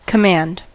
command: Wikimedia Commons US English Pronunciations
En-us-command.WAV